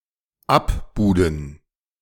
English: Pronunciation recording of German verb "abbuden", IPA: /ˈapˌbuːdn̩/. Male voice, recorded by native German speaker from Berlin, Germany.
Männliche Stimme, aufgenommen von deutschem Muttersprachler aus Berlin, Deutschland.
recorded with Røde NT-USB and Audacity